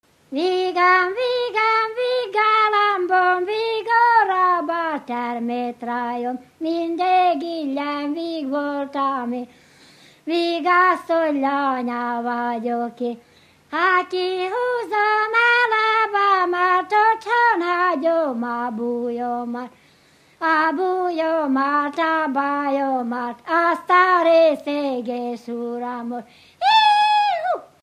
Felföld - Nógrád vm. - Hollókő
Műfaj: Dudanóta
Stílus: 6. Duda-kanász mulattató stílus
Szótagszám: 8.8.8.8
Kadencia: 5 (1) 1 1